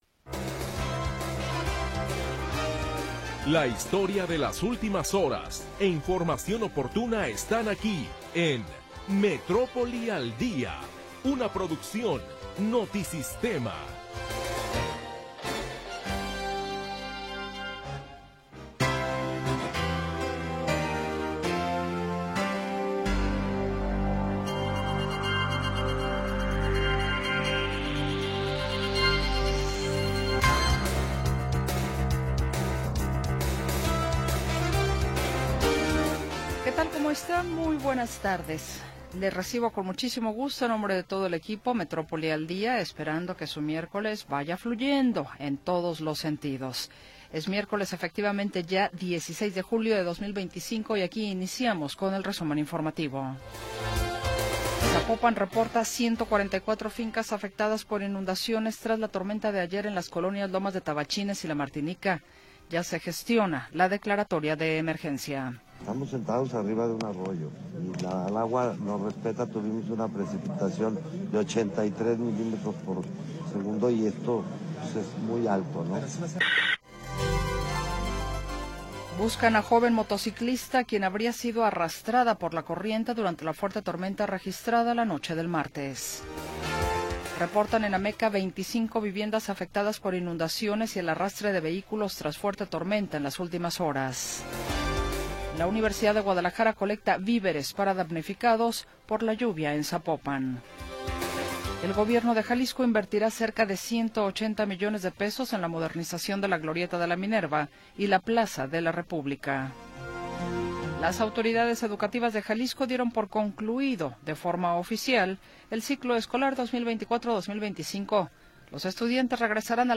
Primera hora del programa transmitido el 16 de Julio de 2025.